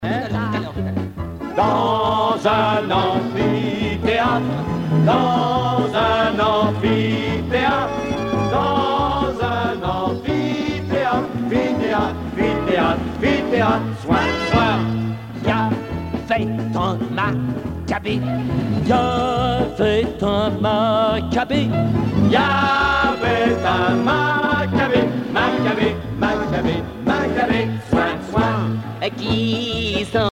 danse : marche
Genre strophique